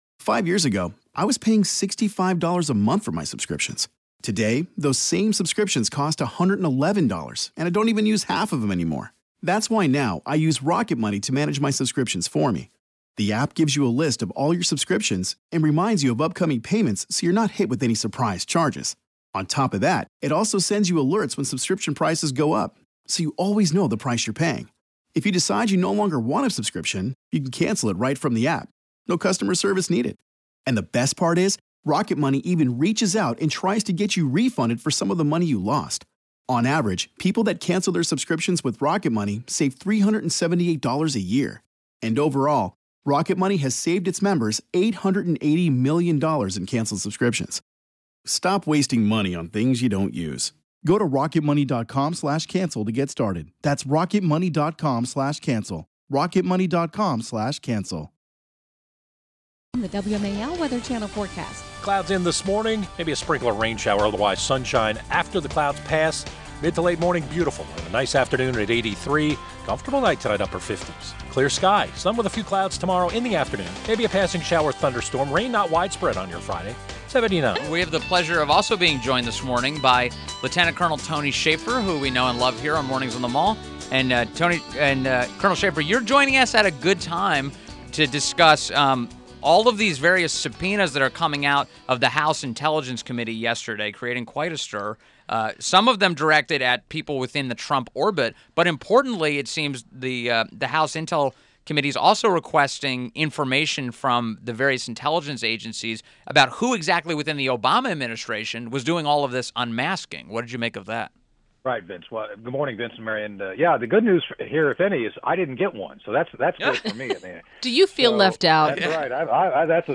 WMAL Interview – LT. COL TONY SHAFFER 06.01.17